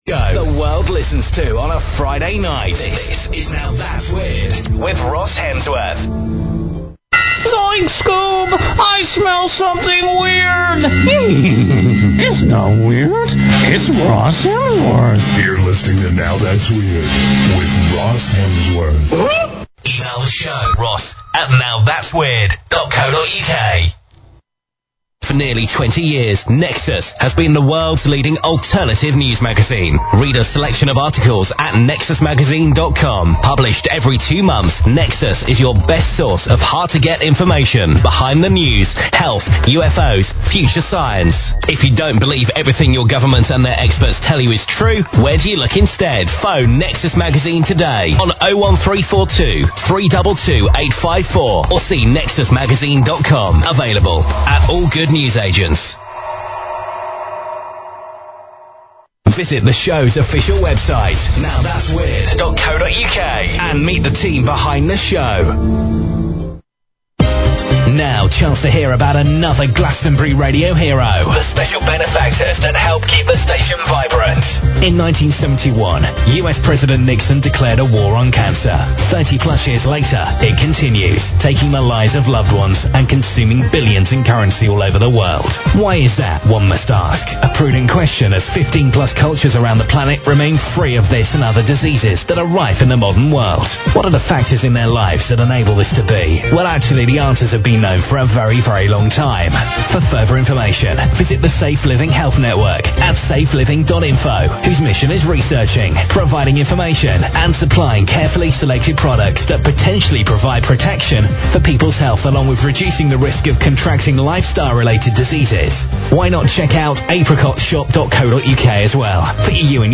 Talk Show Episode, Audio Podcast, Now_Thats_Weird and Courtesy of BBS Radio on , show guests , about , categorized as